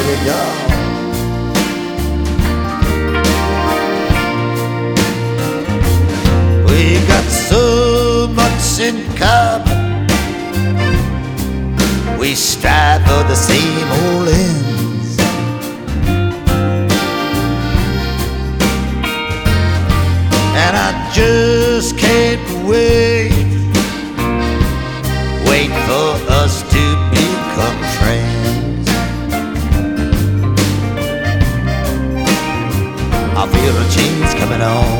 Жанр: Рок / Фолк